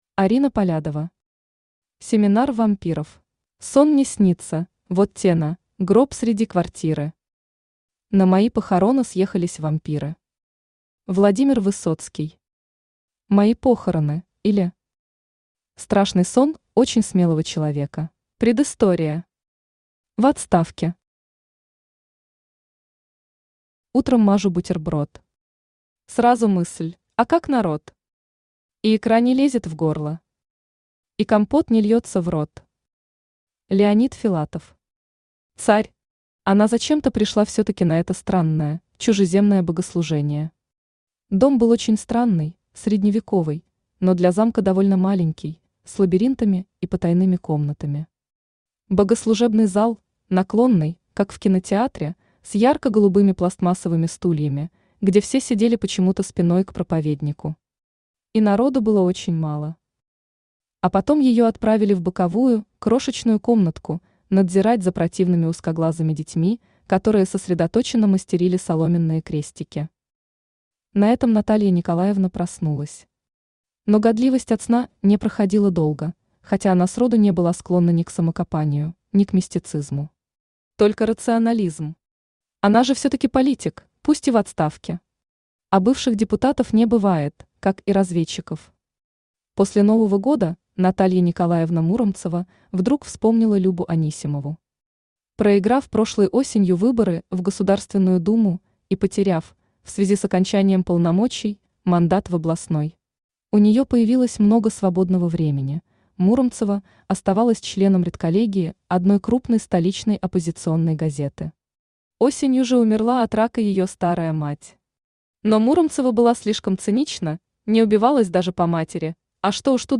Aудиокнига Семинар вампиров Автор Арина Полядова Читает аудиокнигу Авточтец ЛитРес.